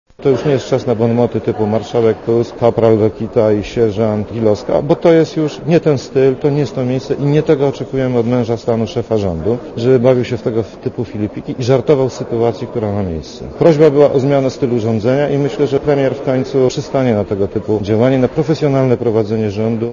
– mówi Wiesław Kaczmarek.